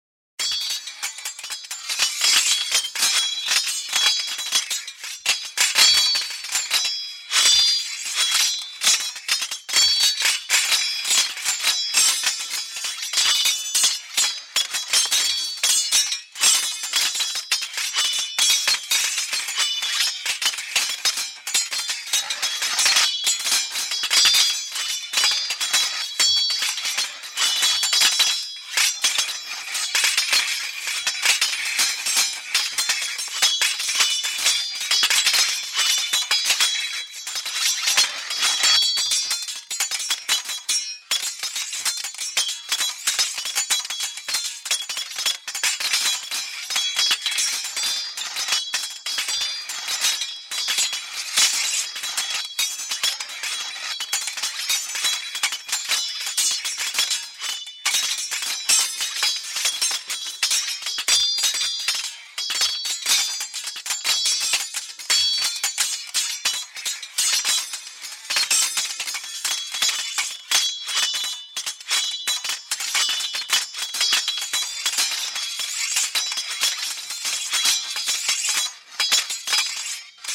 Âm thanh chiến đấu, đánh nhau bằng kiếm